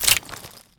Index of /server/sound/weapons/cw_mr96
roundsin.wav